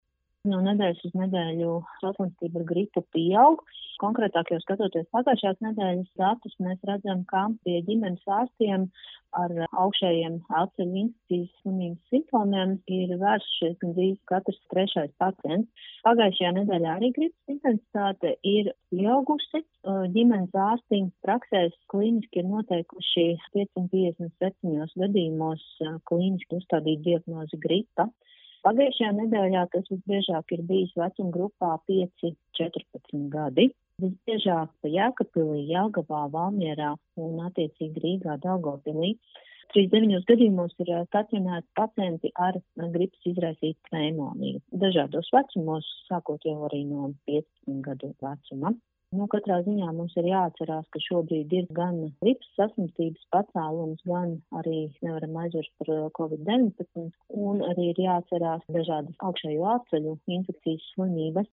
RADIO SKONTO Ziņās par pieaugošo saslimstību ar gripu